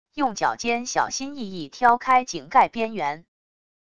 用脚尖小心翼翼挑开井盖边缘wav音频